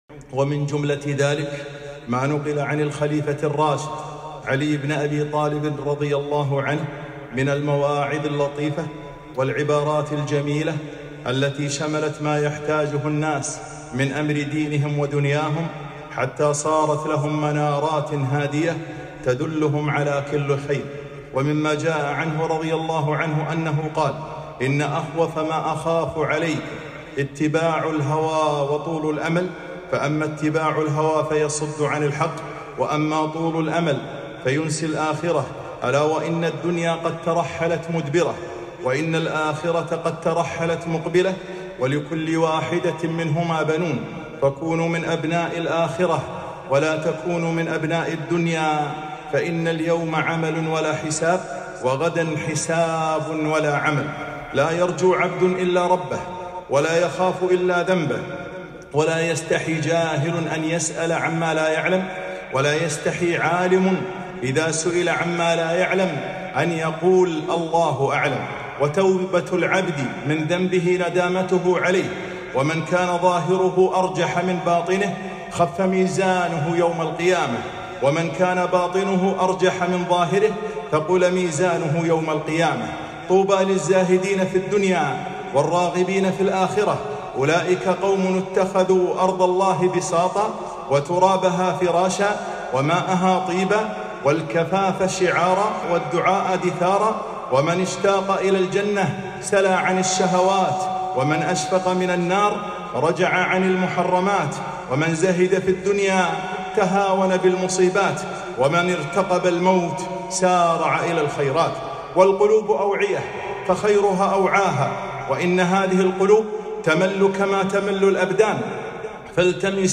خطبة - من مواعظ علي بن أبي طالب رضي الله عنه